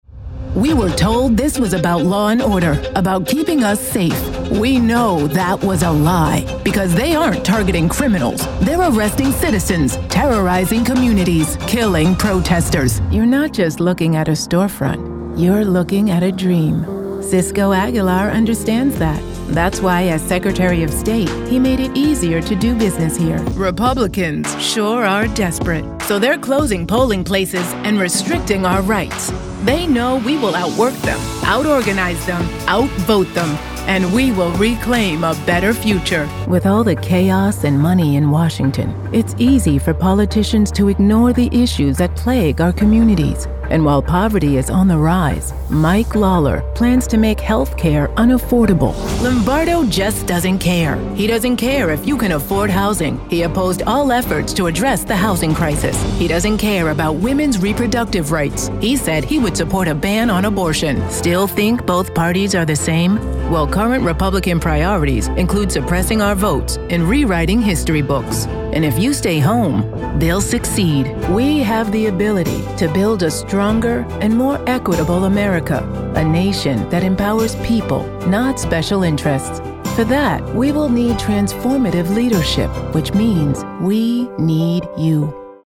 POLITICAL VOICEOVER SERVICES
BROADCAST QUALITY HOME STUDIO
• Sound Isolating Booth
• Sennheiser MK4 Mic
Create real change with a voice that can be inspirational and trustworthy, knowledgeable and caring, or direct and confident.
A PROUD AFRICAN AMERICAN VOICE ACTOR